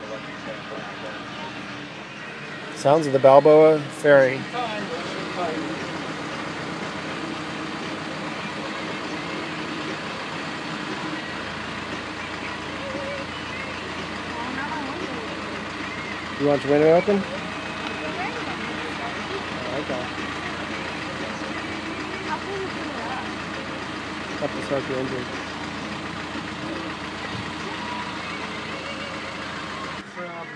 The sound of the Balboa Island Ferry